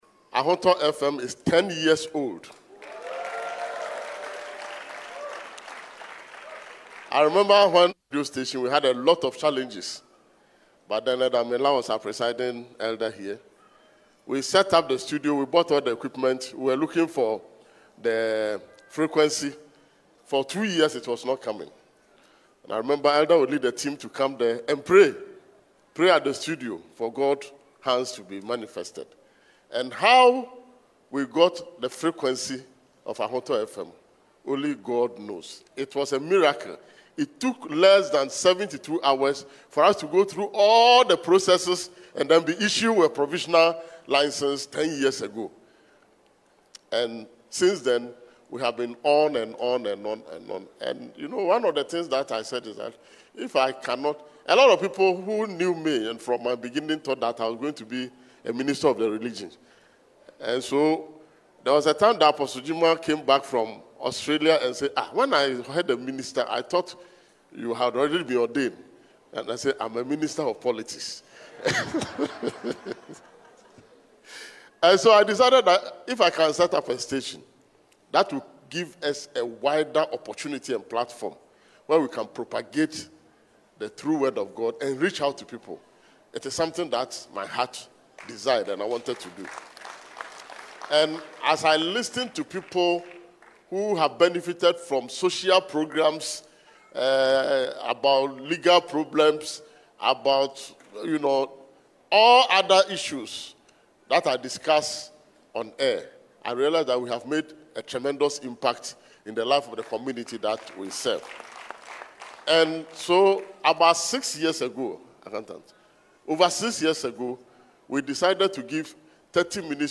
SAKUMONO, Ghana, 28 December: Ahotor 92.3 FM, a subsidiary of Universal Multimedia, has successfully climaxed its 10th anniversary celebrations with a special thanksgiving service held at the Pentecost International Worship Centre (PIWC), Sakumono Branch.